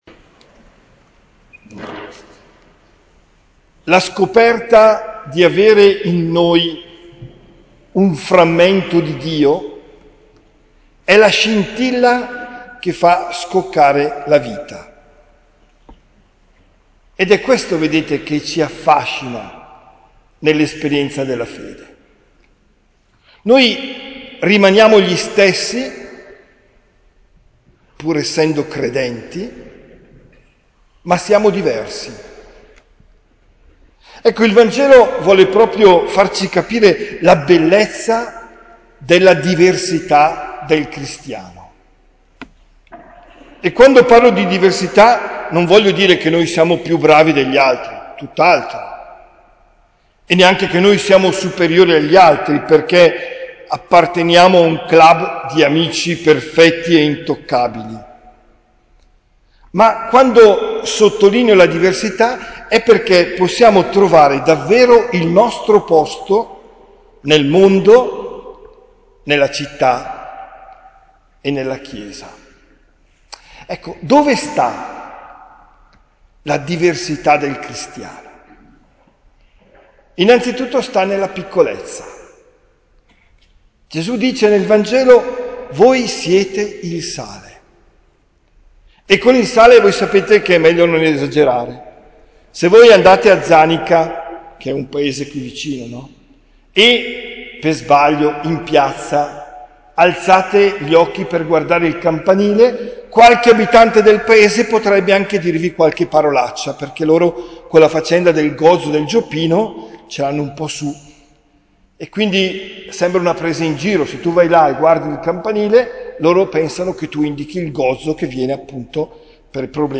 OMELIA DEL 5 FEBBRAIO 2023